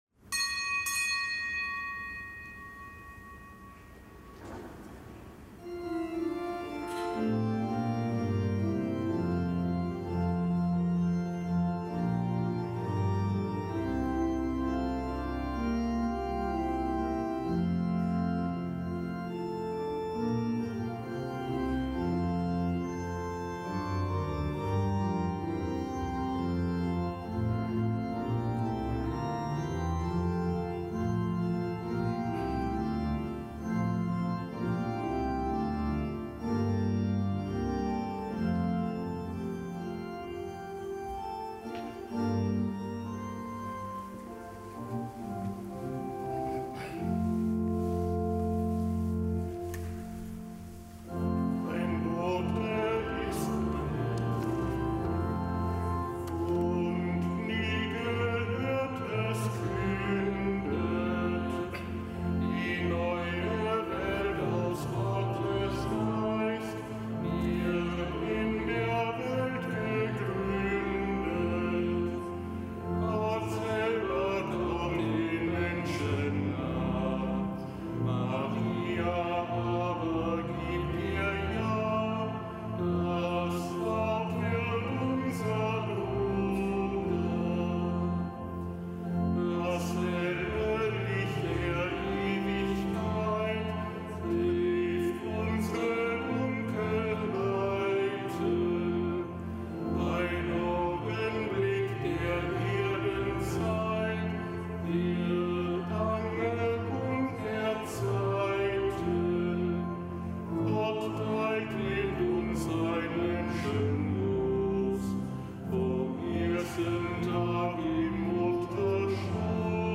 Kapitelsmesse aus dem Kölner Dom am Hochfest Verkündigung des Herrn.